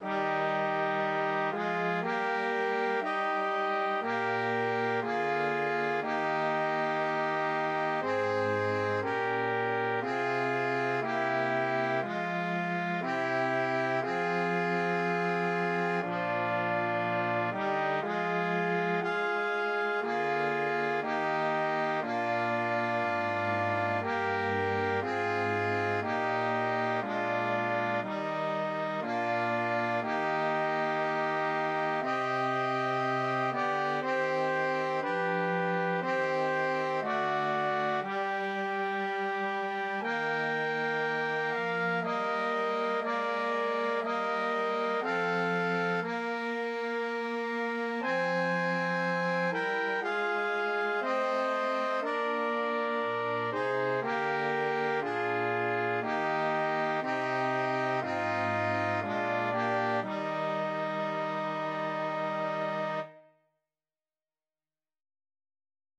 Vegyeskarra